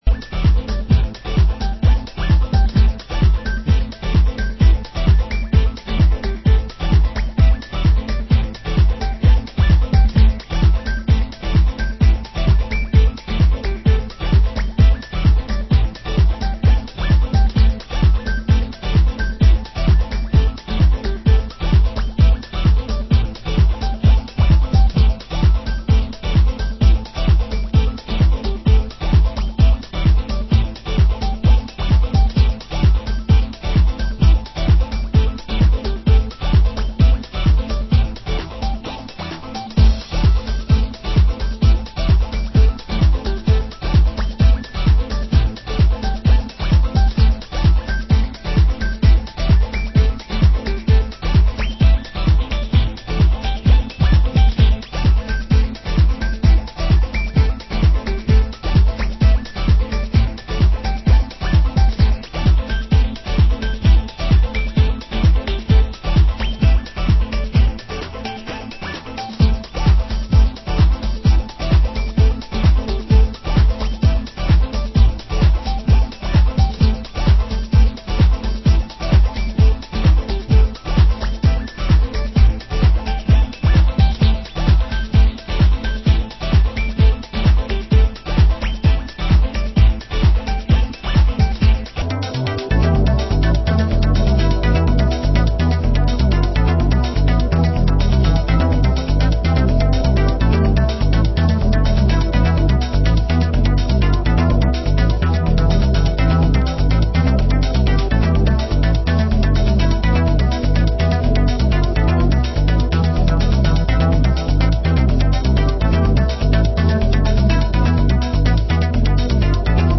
Format: Vinyl 12 Inch
Genre: Euro Techno